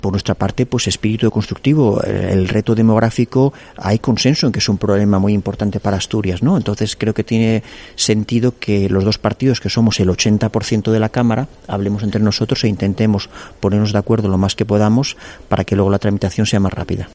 Diego Canga destaca la importancia de que PSOE y PP alcancen consensos sobre el reto demográfico